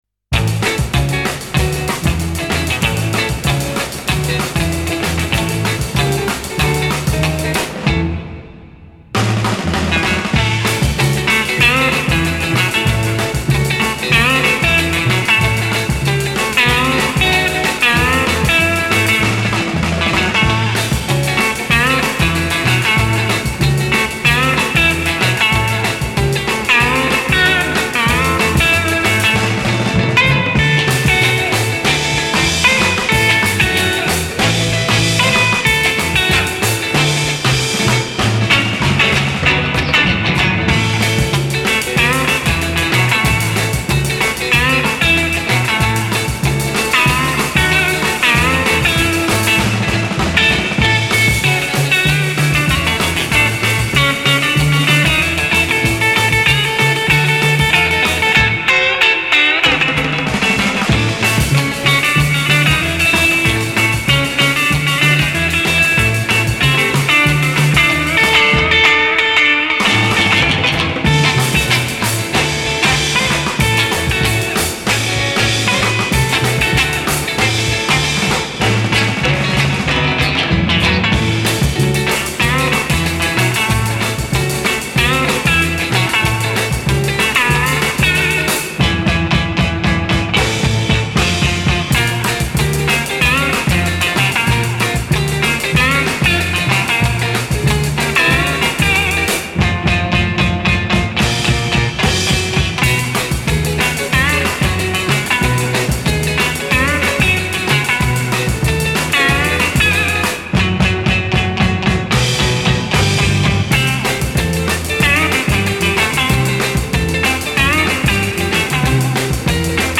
инструментальная группа 60-х годов XX века.